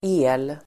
Uttal: [e:l]